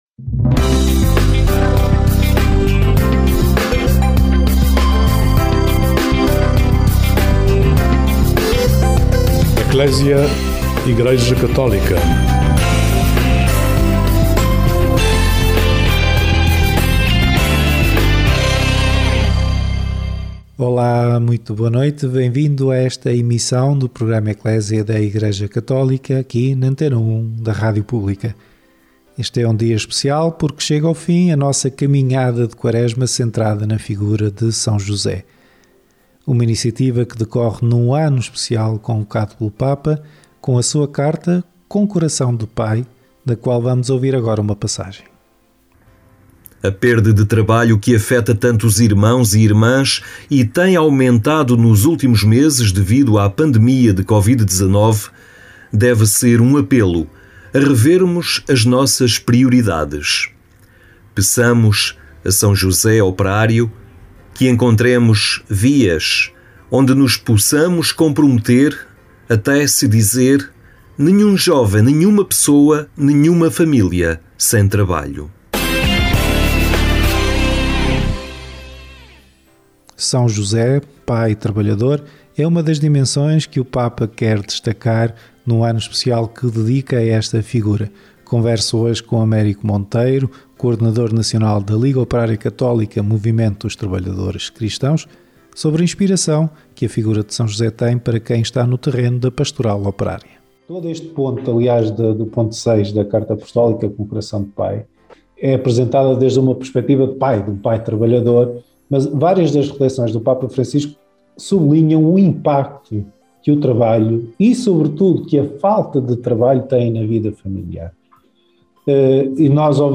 O responsável foi convidado pelo Programa ECCLESIA, na Antena 1 da rádio pública, para falar sobre o ponto 6 da carta ‘Com Coração de Pai’, escrita pelo Papa, que apresenta São José como “pai trabalhador”.